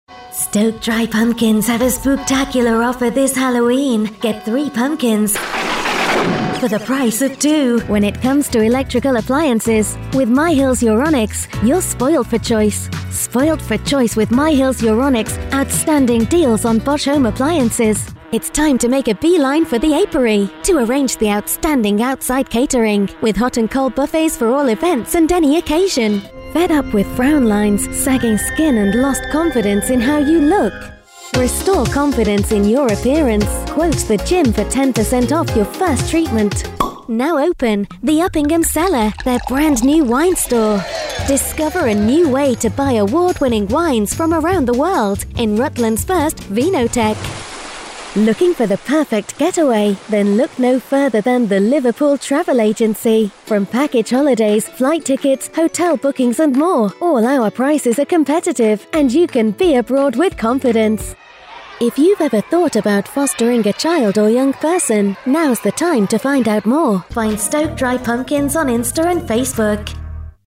Working from my own broadcast quality studio, I provide voiceovers for all kinds of clients from Independent Organisations to Global Brands.
I can read with a neutral UK accent or with one of my various character voices and regional accents, including authentic Northern dialects of Manchester, Liverpool, Cheshire and Yorkshire, as well as more off the wall characters for those magical, memorable reads!
Sprechprobe: Werbung (Muttersprache):
My voice can be described as authentic, sincere, assured and clear, an excellent choice for Radio & TV Commercials, Corporate and Explainer Videos, E-Learning & Training Narration, Telephone IVR and On-Hold Voiceovers, I have numerous character voices available for Commercials, Animation & Gaming too.
Commercial Showreel 2023.mp3